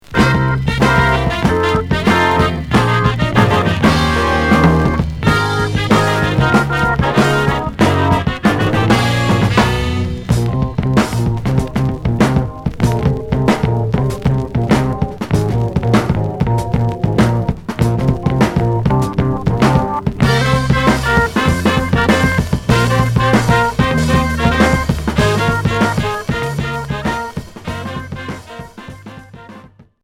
Groove progressif